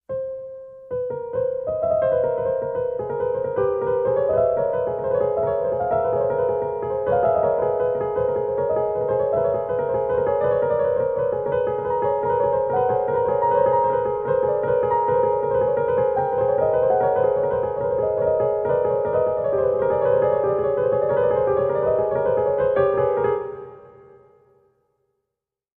Music Bed; Dissonant Piano Tinkles For Horror Effect.